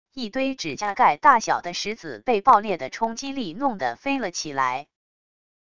一堆指甲盖大小的石子被爆裂的冲击力弄的飞了起来wav音频